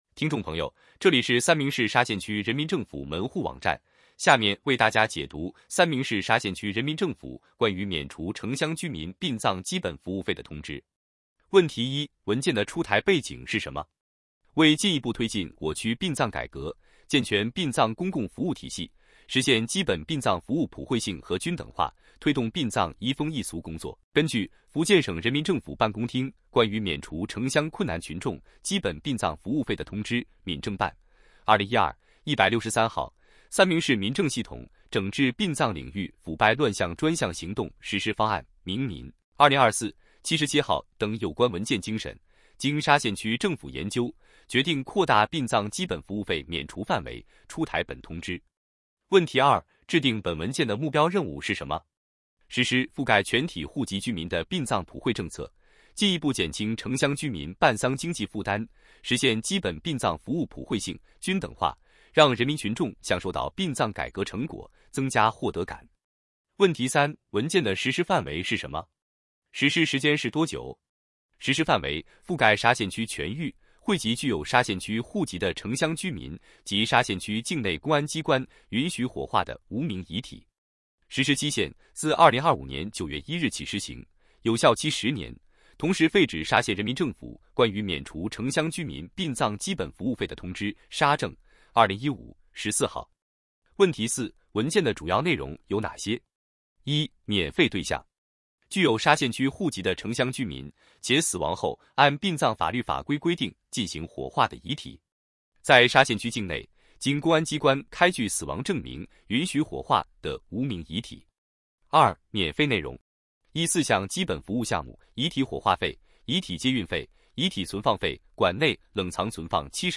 音频解读：三明市沙县区人民政府关于免除城乡居民殡葬基本服务费的通知